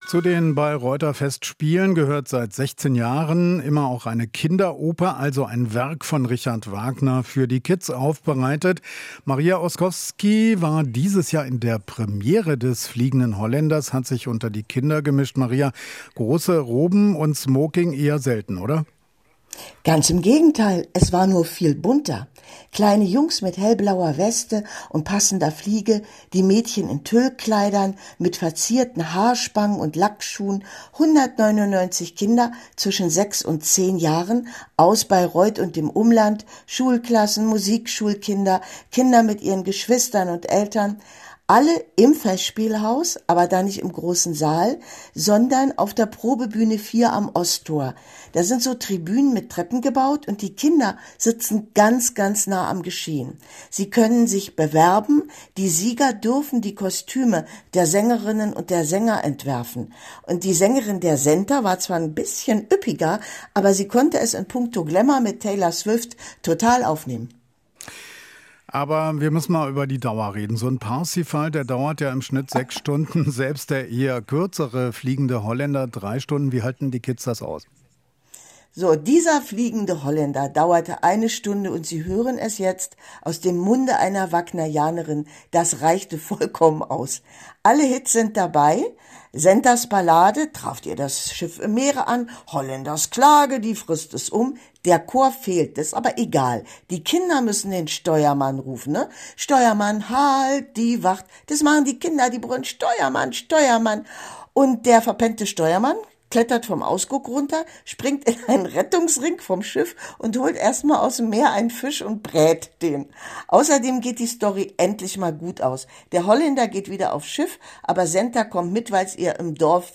Es musiziert in gewohnter Manier das Brandenburgische Staatsorchester.